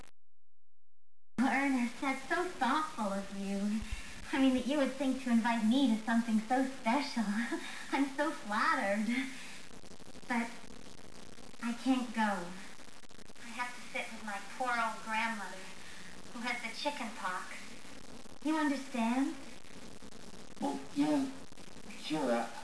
Voice Actress Wav from "Slam Dunk Ernest"
Mouse has a southern (american) accent ('sugah'), though her southern roots does not stop at her voice.